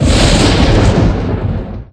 Thunder9.ogg